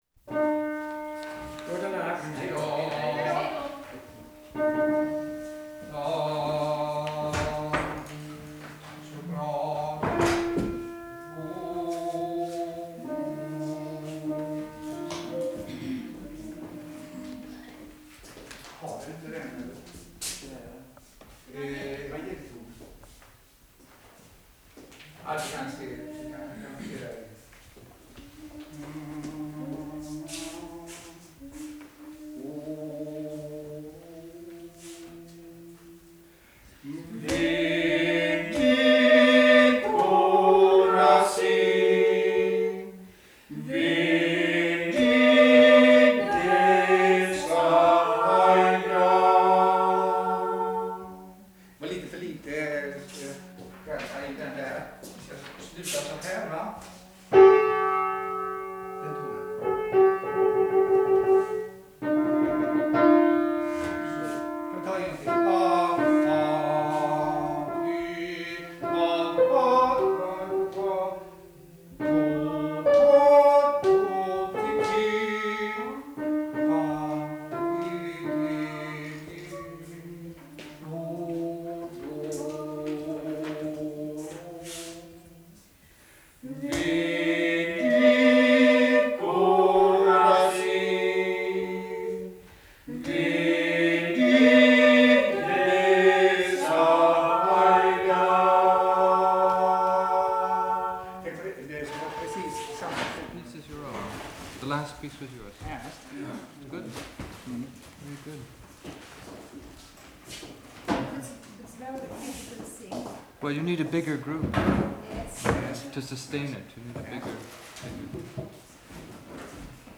SKRUV, Sweden
the glassworks (acid bath); near the railway station (a passing train); village stream; in a home; the main street at night (footsteps on frozen ground, factory ambience in background); railway station (local electric train); brewery (creek and shift whistle)